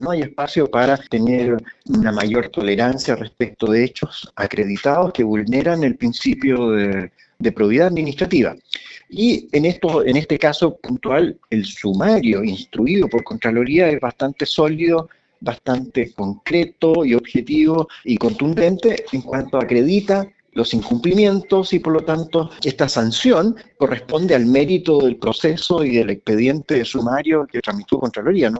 En conversación con La Radio